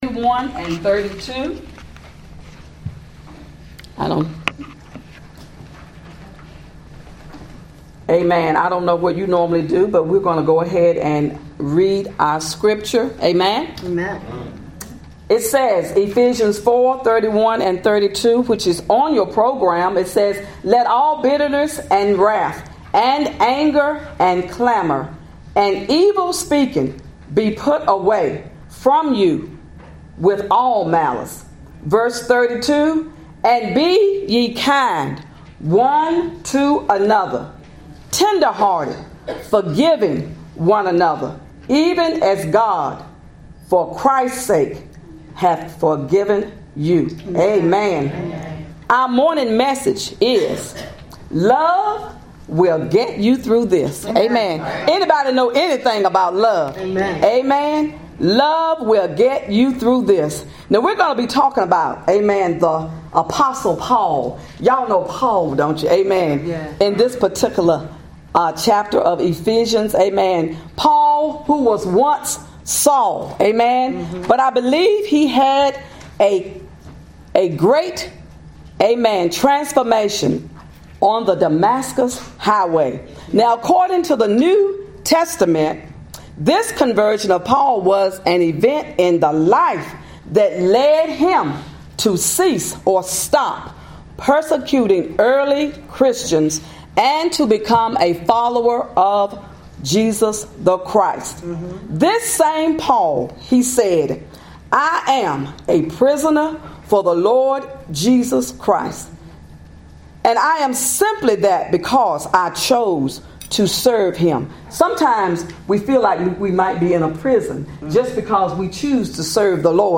Women’s Day Message